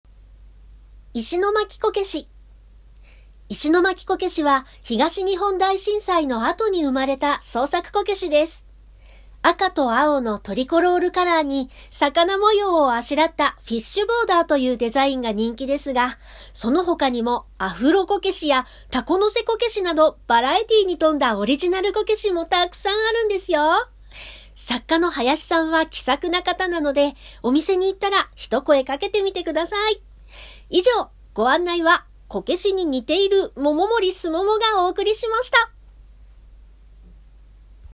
下記のMAPが示している位置にあるＱＲコードを読み取ると、声優さんたちが石巻の街中を案内してくれるここだけでしか聞くことのできない「お宝ボイス」を聴くことができました！